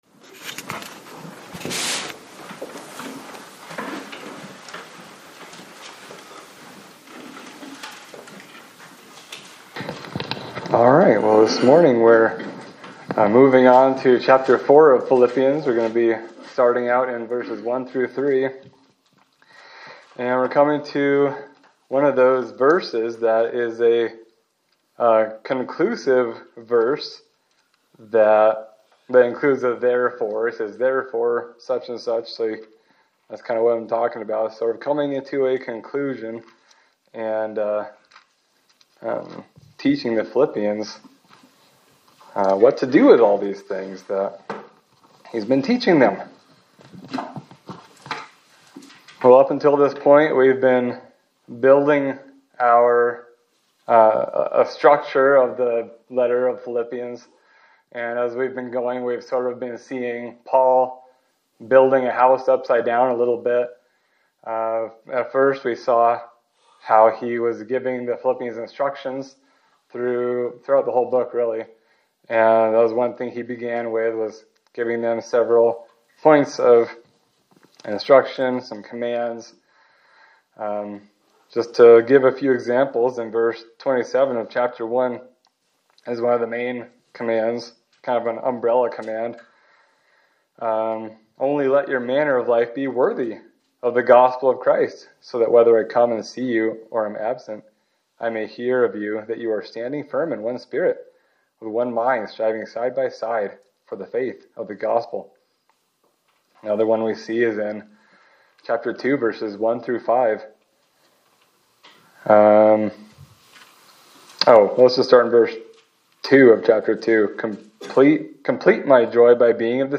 Sermon for December 28, 2025
Service Type: Sunday Service